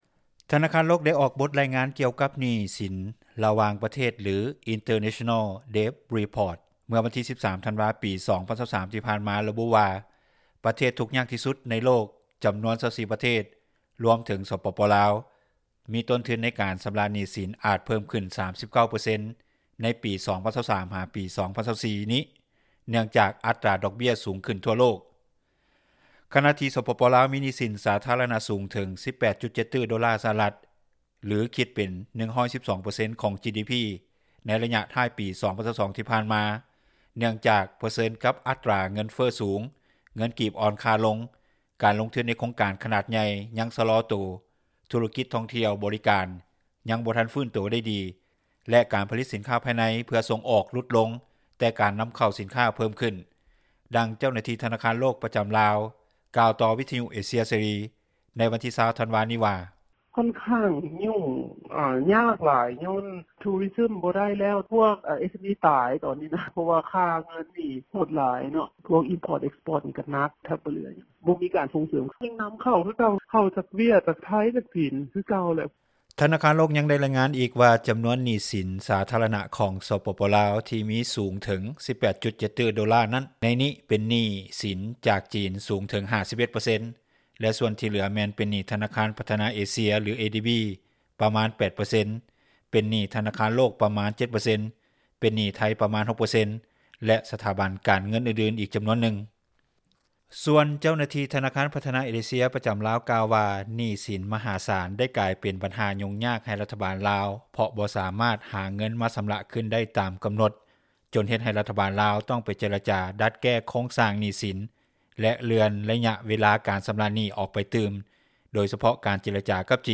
ດັ່ງເຈົ້າໜ້າທີ່ທະນາຄານ ພັທນາເອເຊັຽ ກ່າວໃນມື້ດຽວກັນວ່າ:
ດັ່ງຊາວນະຄອນຫຼວງວຽງຈັນ ກ່າວຕໍ່ວິທຍຸເອເຊັຽເສຣີ ໃນມື້ດຽວກັນວ່າ:
ດັ່ງຊາວນະຄອນຫຼວງວຽງຈັນ ອີກທ່ານນຶ່ງກ່າວວ່າ: